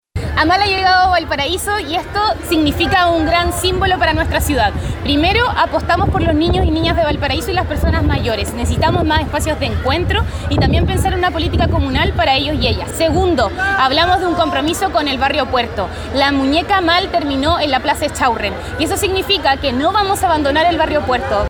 Por su parte, la alcaldesa de la ciudad puerto, Camila Nieto, mencionó que la llegada de Amal representa un símbolo de esperanza para los niños, niñas y personas mayores de Valparaíso, asegurando que se necesitan más espacios de encuentro de esta índole.